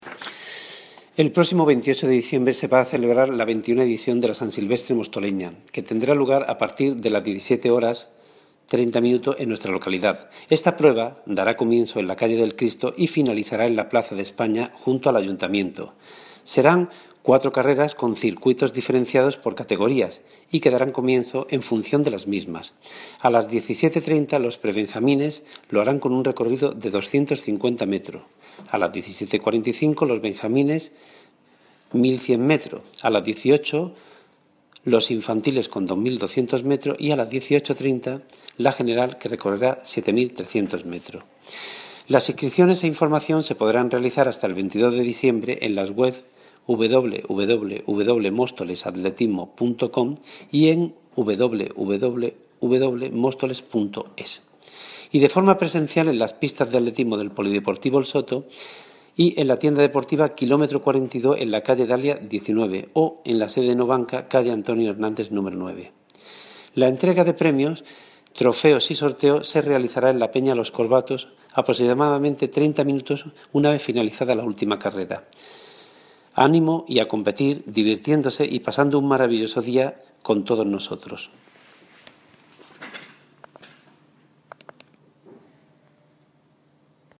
Agustín Martín (Concejal de Deportes) sobre la San Silvestre
Agustin Martin Concejal de deportes.mp3